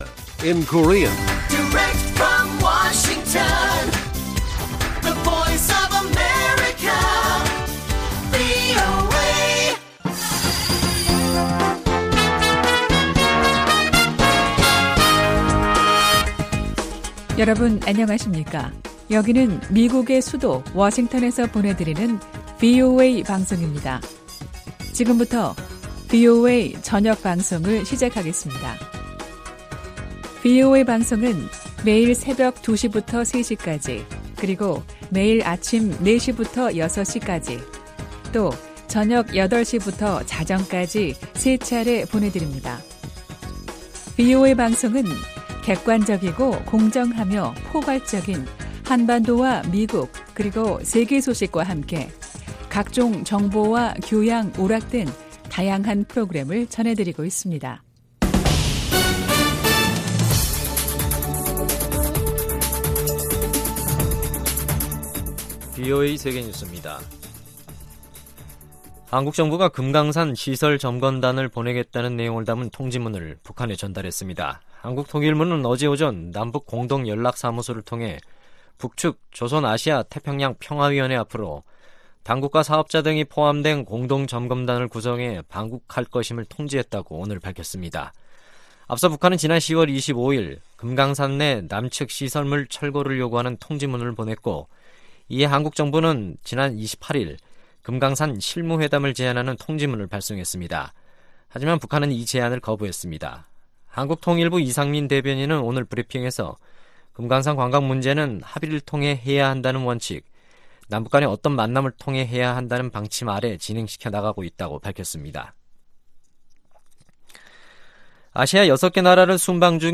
VOA 한국어 간판 뉴스 프로그램 '뉴스 투데이', 2019년 11월 6일 1부 방송입니다. 한국을 방문한 데이비드 스틸웰 미 국무차관보는 한-일 정상의 지난 4일 방콕 회동이 매우 고무적이었다고 말했습니다. 한반도 평화체제는 북한에 대한 트럼프 대통령의 비전이라고 미 국무부 북한담당 부차관보가 밝혔습니다.